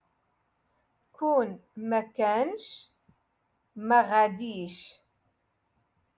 Moroccan Dialect- Rotation Six - Lesson Six